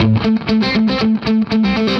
AM_HeroGuitar_120-A01.wav